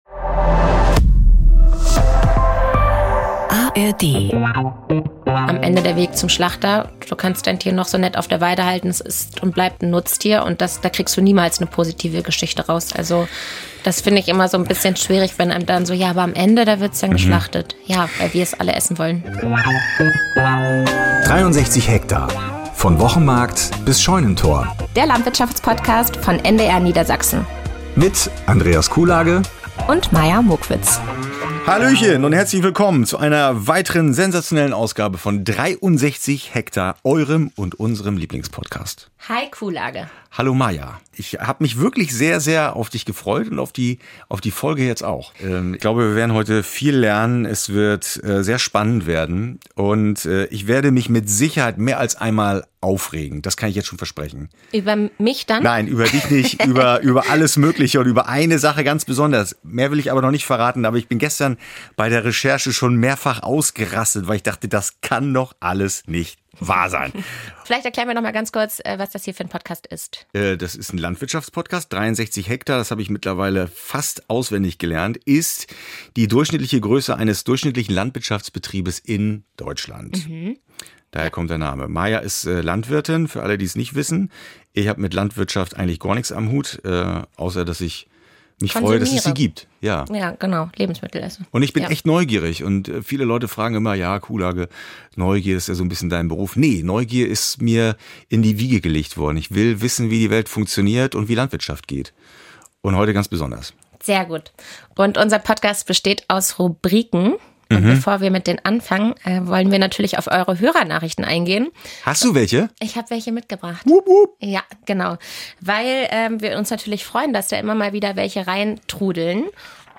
Ein Podcast für alle zwischen Wochenmarkt bis Scheunentor - denn niemand kann ohne Landwirtschaft.